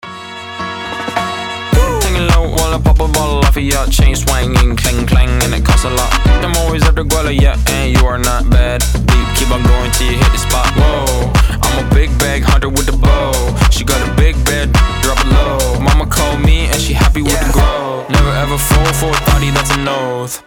ритмичные
веселые
заводные
house